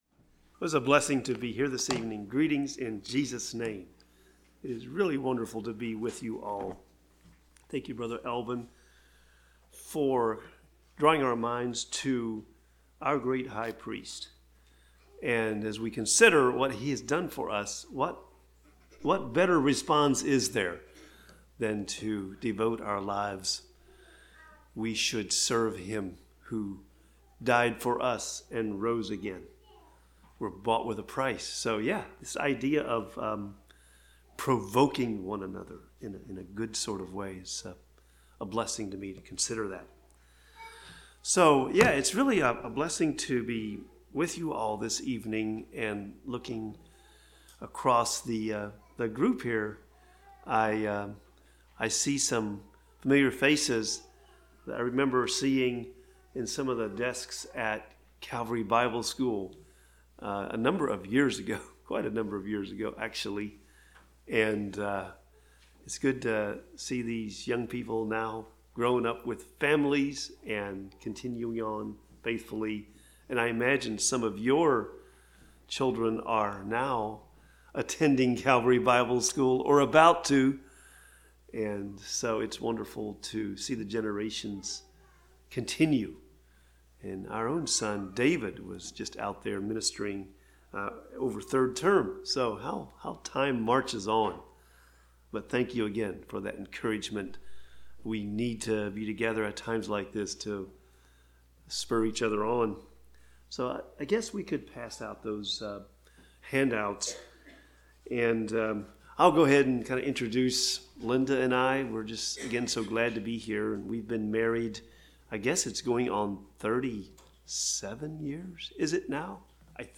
Service Type: Spring Conference Meetings Topics: Conversion , The Gospel « The Bride of Christ